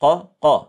Forskare har upptäckt att den exakta tidsmätningen som en person kan efterlikna och uppnå är rörelse, det vill säga, när en person säger: قَ قَ (QaQa)